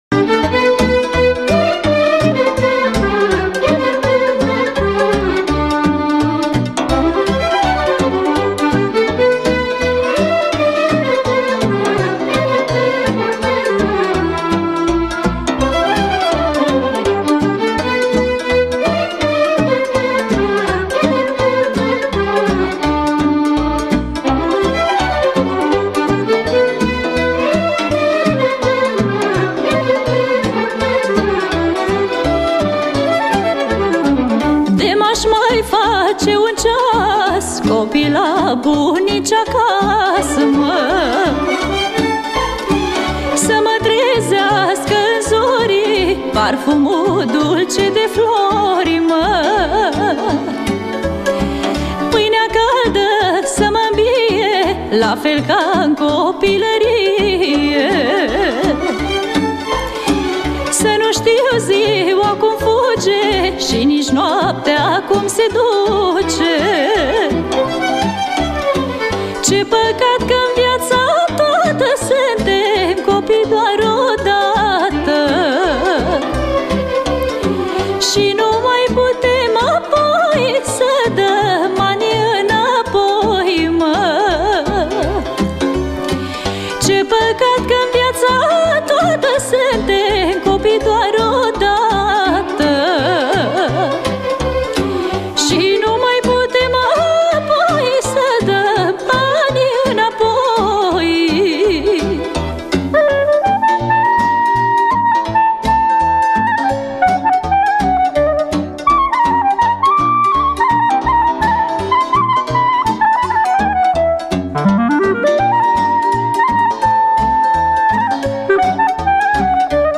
Categoria: Populara New